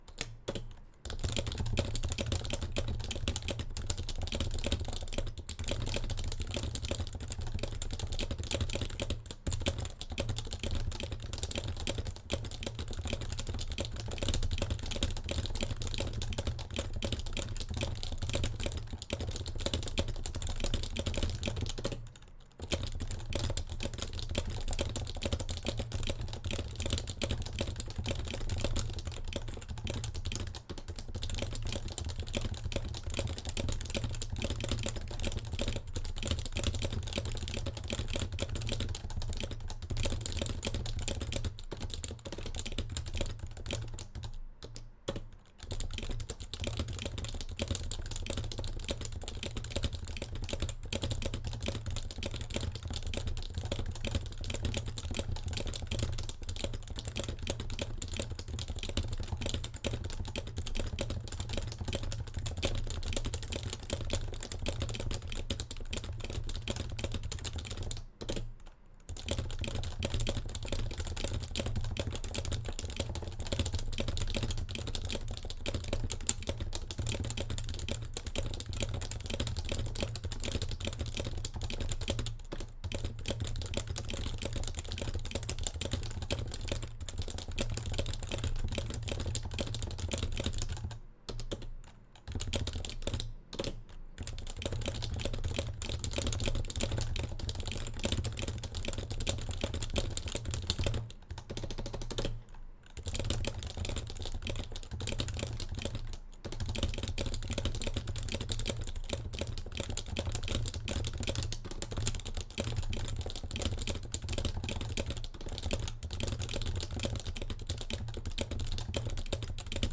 keyboard_typing.mp3